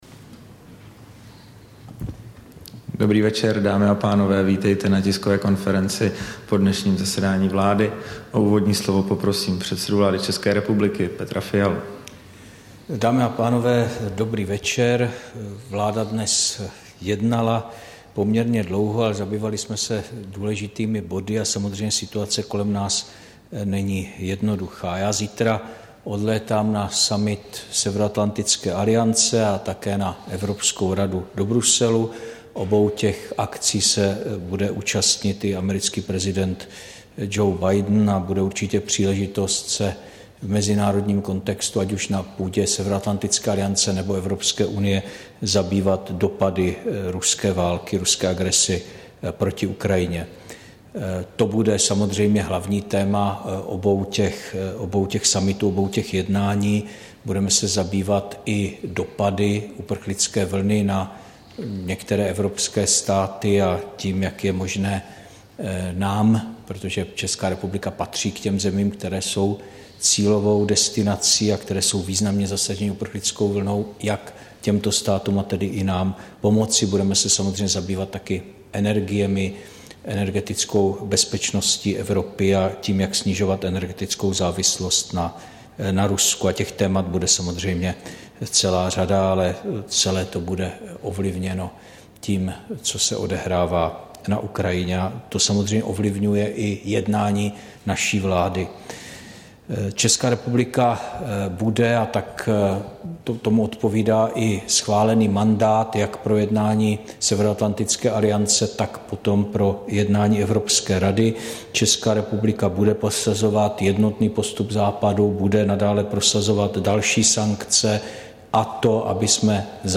Tisková konference po jednání vlády, 23. března 2022